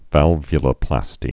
(vălvyə-lə-plăstē)